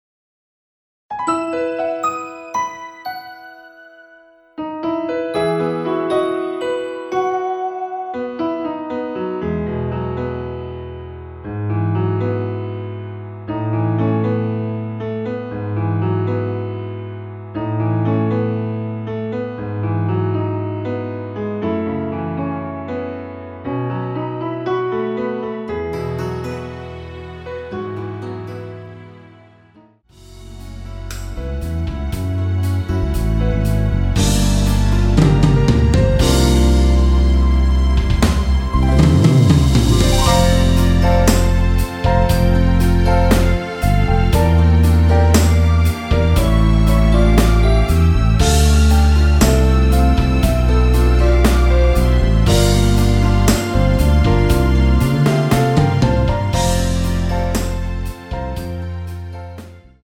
*부담없이즐기는 심플한 MR~
앞부분30초, 뒷부분30초씩 편집해서 올려 드리고 있습니다.
중간에 음이 끈어지고 다시 나오는 이유는